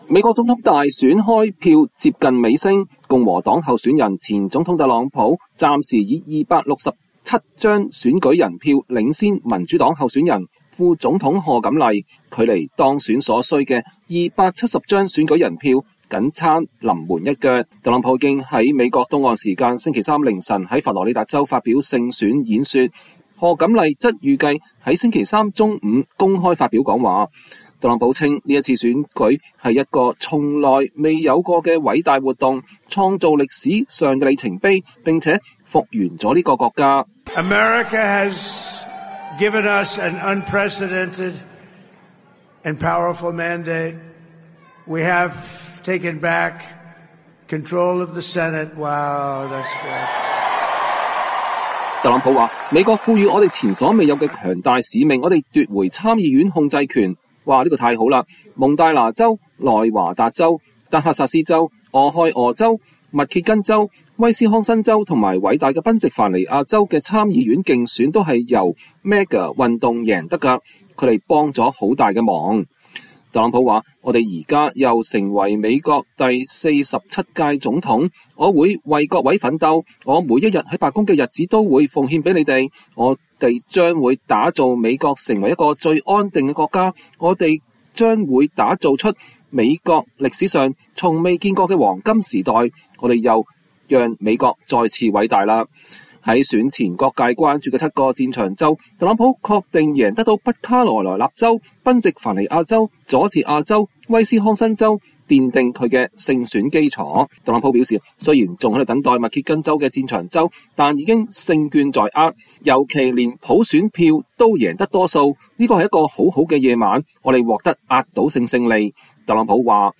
特朗普距離再次當選美國總統僅差臨門一腳，星期三凌晨在佛州發表勝選演說。